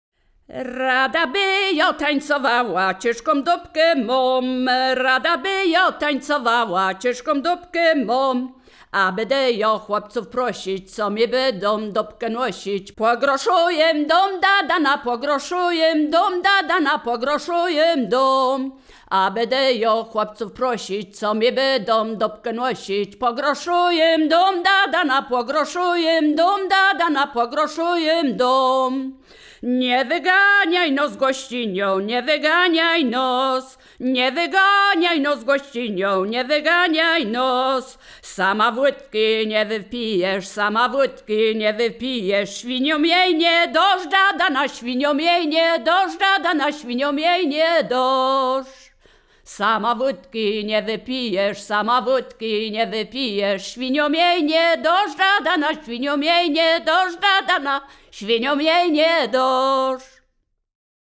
Wielkopolska
województwo wielkopolskie, powiat gostyński, gmina Krobia, wieś Posadowo
żartobliwe przyśpiewki